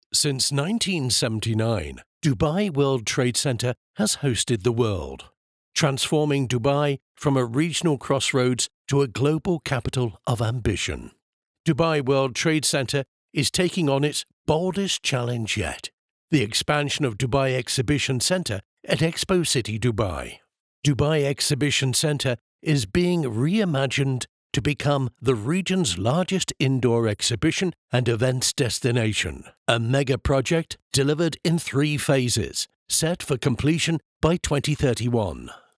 Male
English (British)
Adult (30-50), Older Sound (50+)
Narration
Male Voice Over Talent